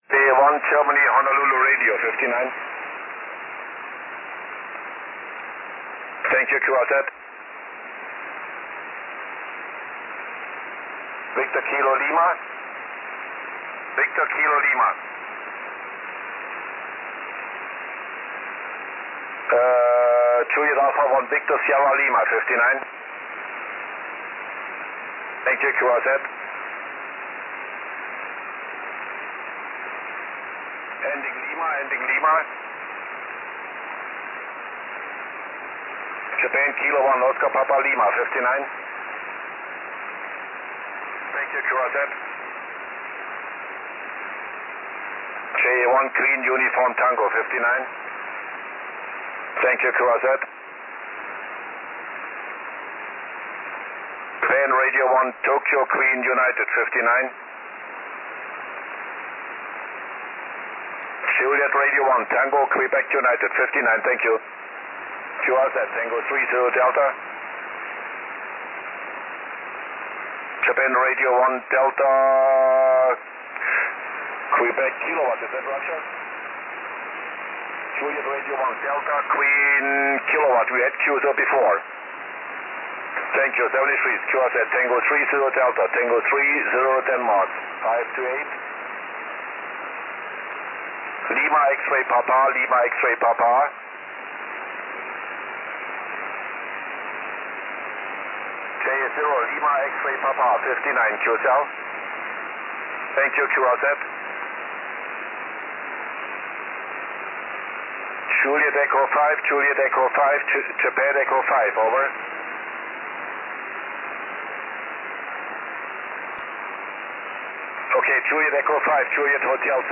Oct/12 0507z T30D 50.120MHz SSB